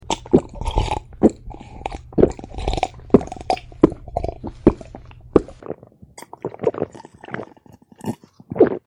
Глоток (пьет воду)
Отличного качества, без посторонних шумов.
350_glotok.mp3